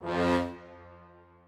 strings4_22.ogg